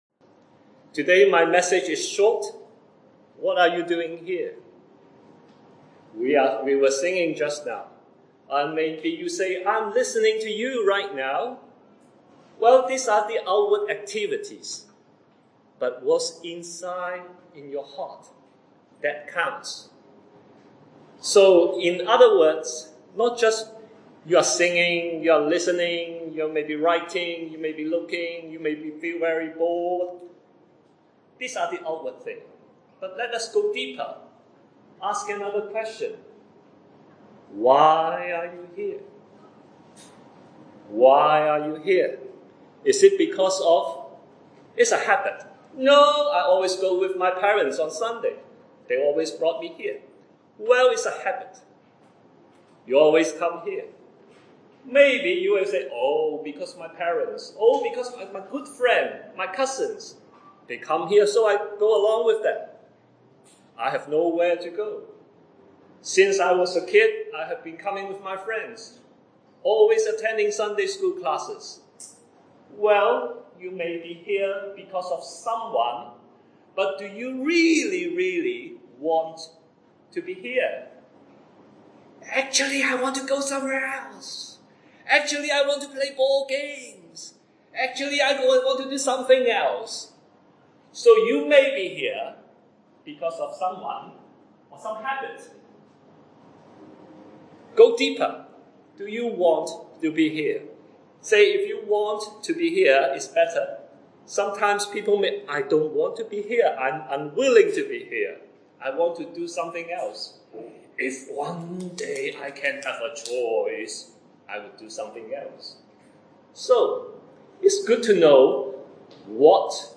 Scripture references: John 1:12, Romans 8:9, Galatians 4:6, John 17:3, 1 Peter 1:23 A short message to consider why we gather in such a manner, and how the reality of the Christian life is relationship with God and not merely participating in religious activities.